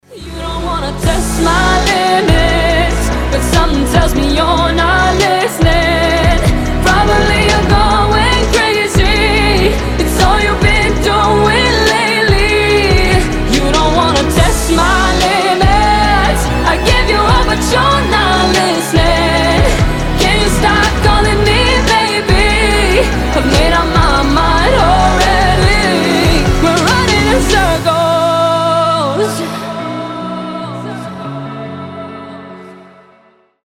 • Качество: 320, Stereo
красивый женский голос